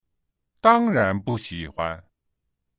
（ダンラン ウオ　　ブー　シーフアン）